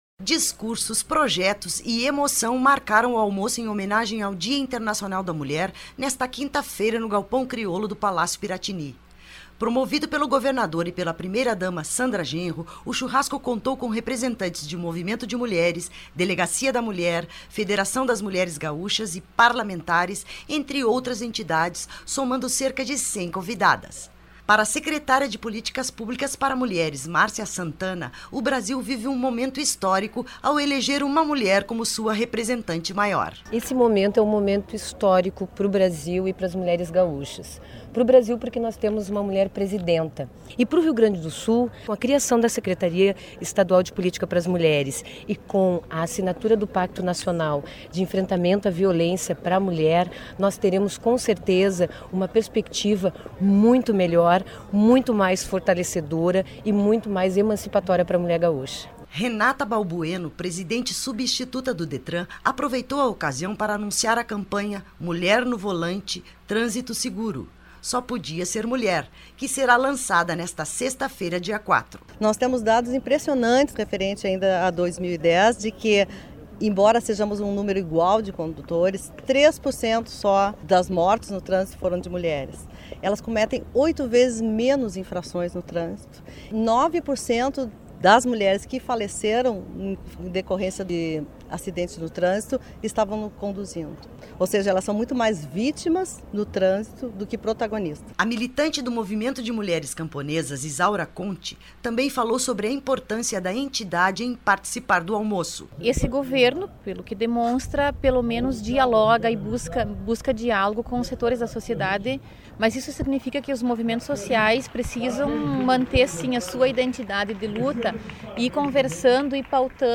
Discursos, projetos e emoção marcaram o almoço em homenagem ao Dia Internacional da Mulher, nesta quinta-feira (3), no Galpão Crioulo, do Palácio Piratini.